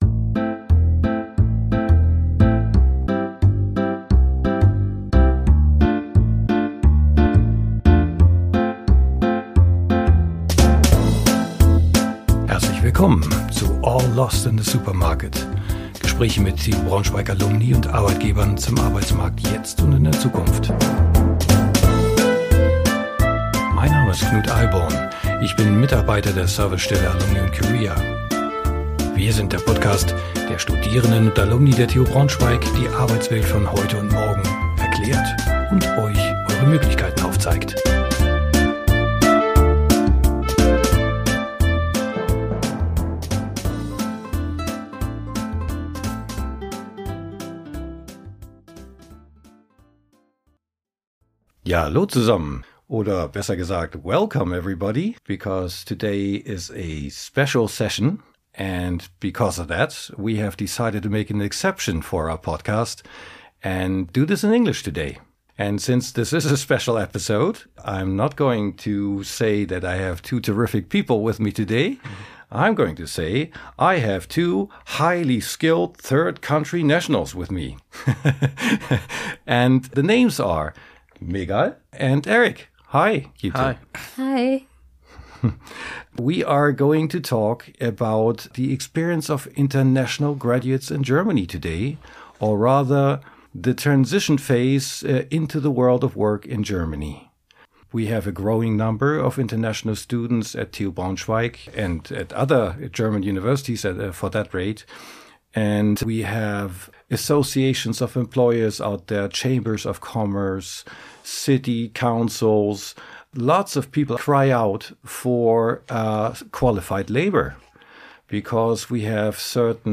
Time to have a chat with two from the target group